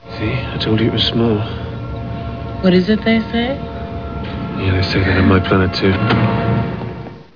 All sounds are of Paul McGann from the telefilm, Doctor Who.
Sounds were originally sampled at 22 kHz, 16-bit mono with GoldWave, then resampled to 11 kHz, 8-bit mono to reduce their file size.